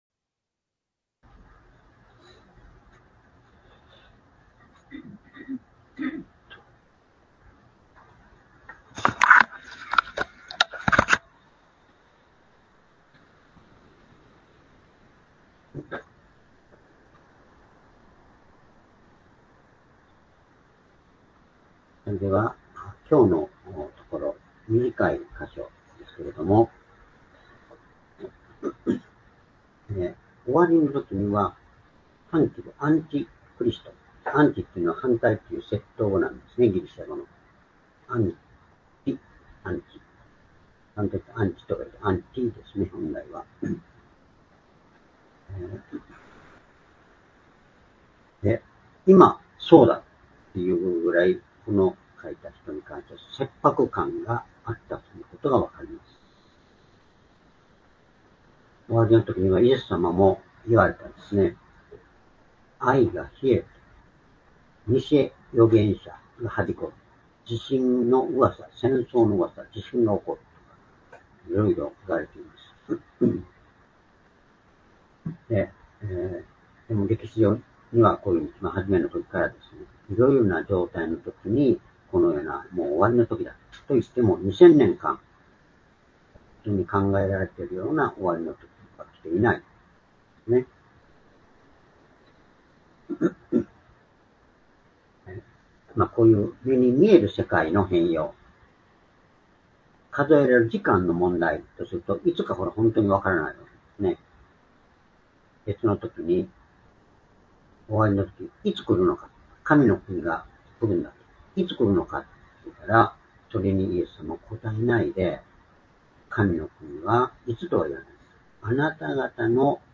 主日礼拝日時 ２０２５年８月２４日（主日礼拝） 聖書講話箇所 「真理とは何か、神から油が注がれている重要性」 Ⅰヨハネ ２の１８-２０ ※視聴できない場合は をクリックしてください。